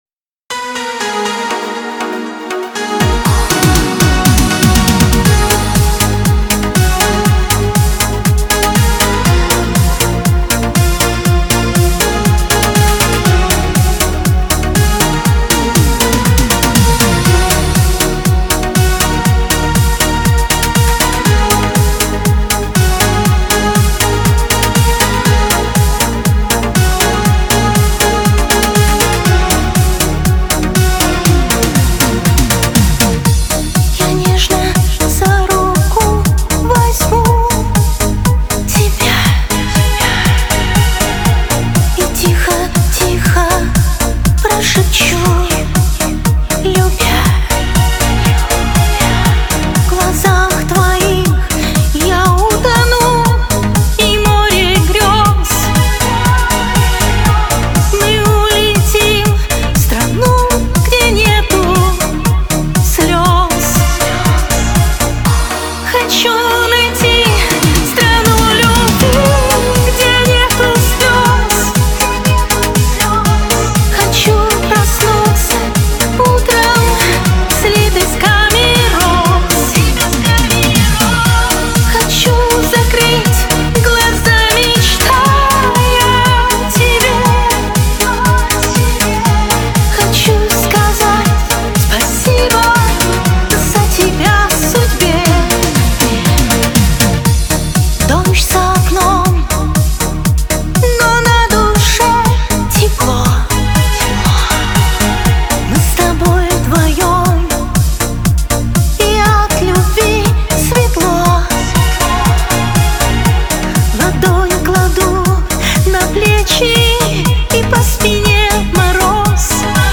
Скачать музыку / Музон / Песни Шансона